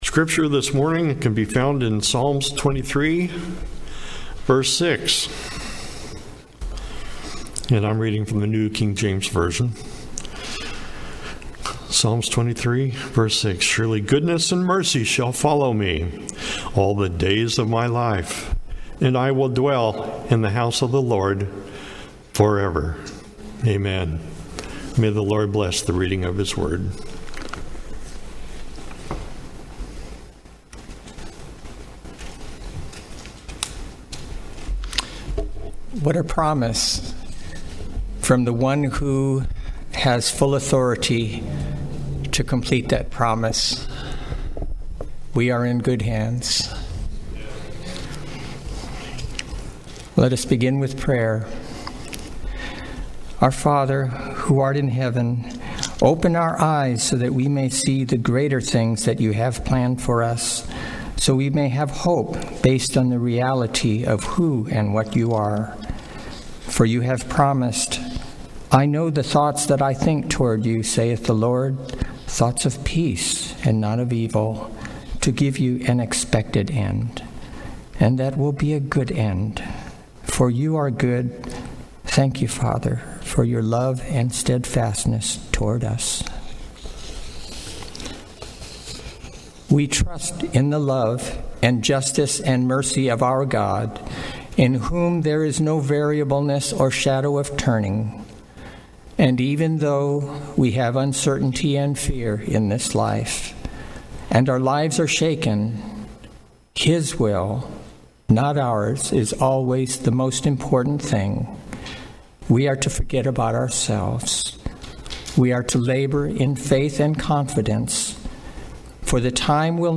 Seventh-day Adventist Church, Sutherlin Oregon
Sermons and Talks 2025